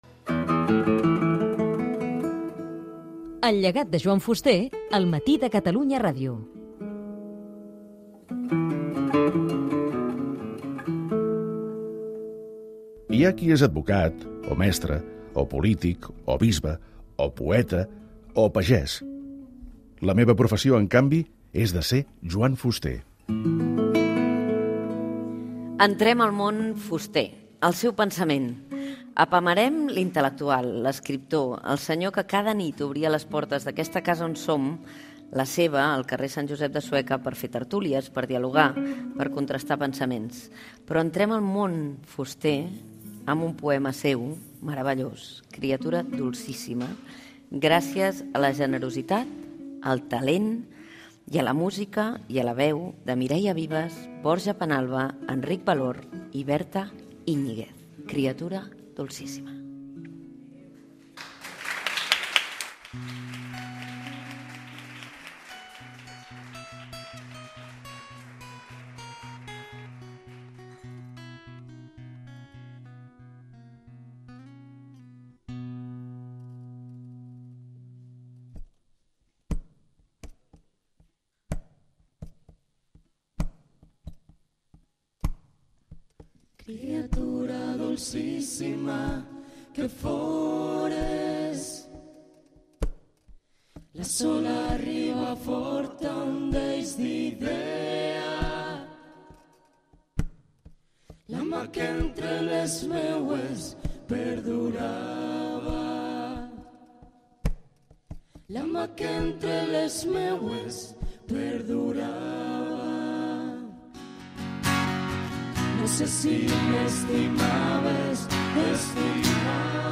Espai fet des de la casa de l'escriptor Joan Fuster a Sueca (València). Un poema seu cantat.